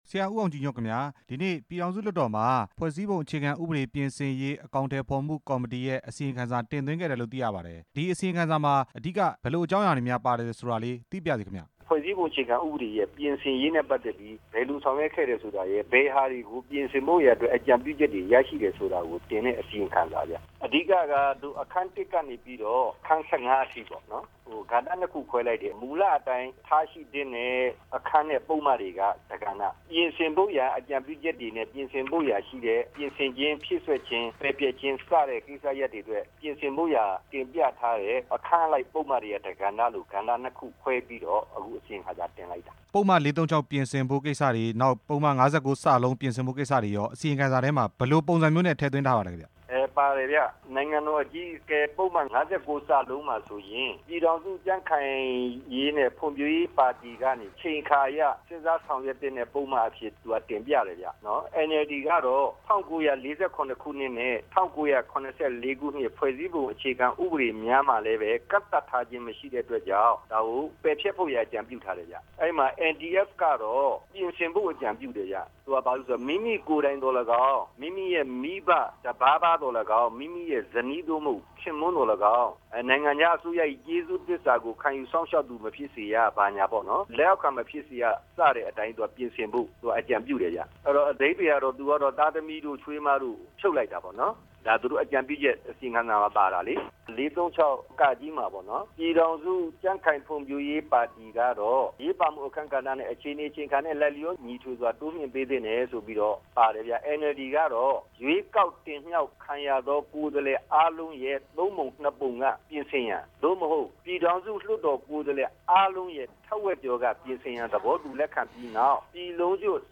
ဦးအောင်ကြည်ညွန့်ကို မေးမြန်းချက်